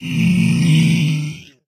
zpigdeath.mp3